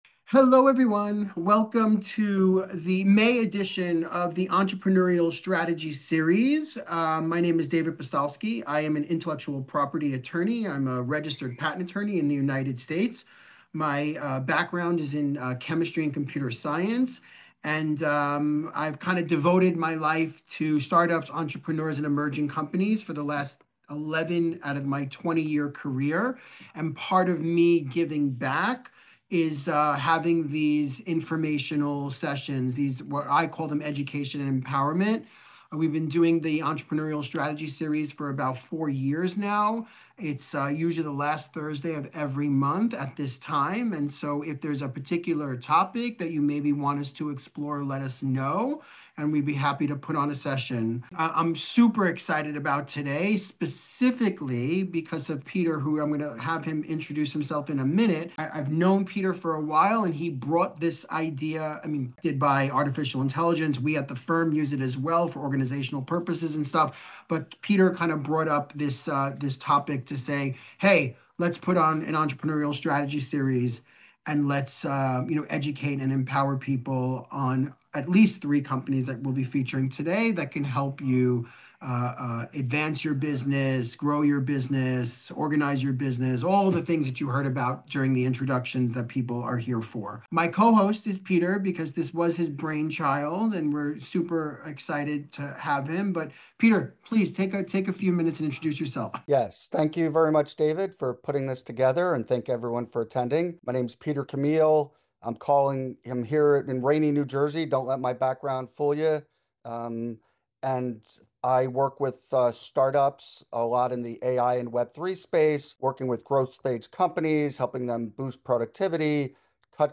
Join us and discover how Artificial Intelligence can be a game-changer for your company's success. During this insightful webinar, we'll explore: Leveraging AI for Data-Driven Decisions: How AI can analyze vast amounts of data, providing you with actionable insights to make smarter, faster business decisions.